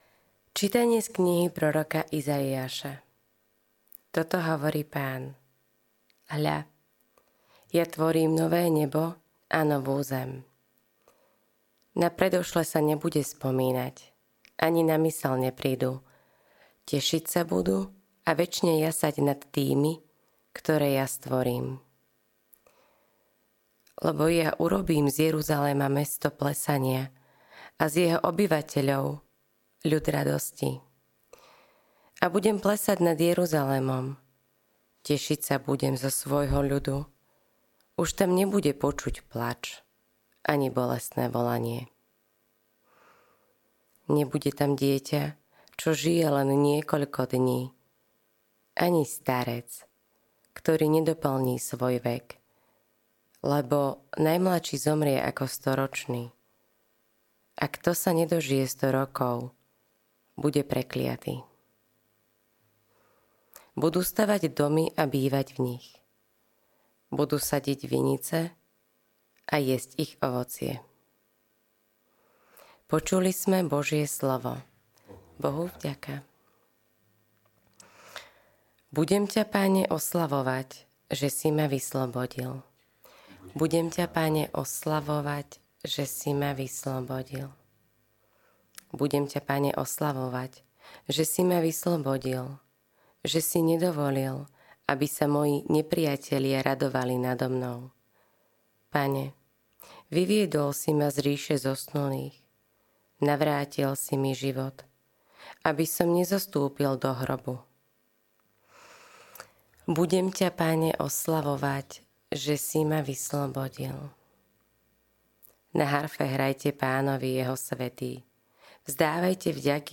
LITURGICKÉ ČÍTANIA | 31. marca 2025